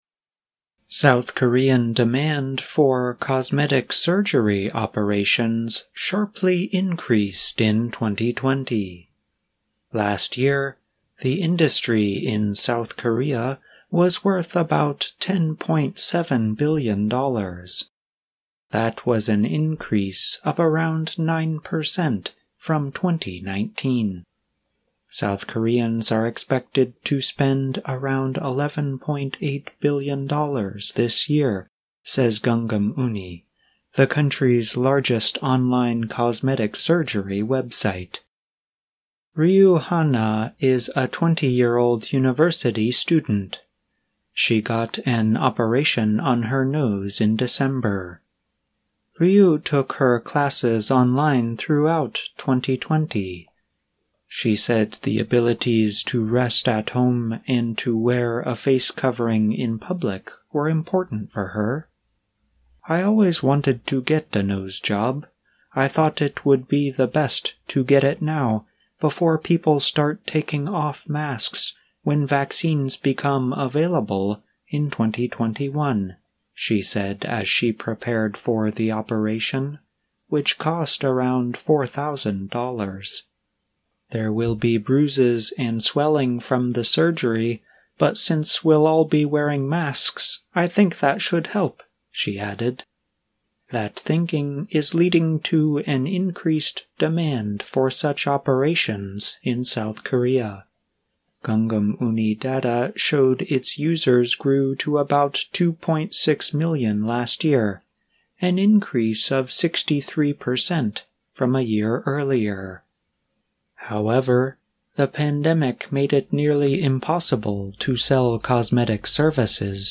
慢速英语:新冠疫情期间韩国整容手术需求增多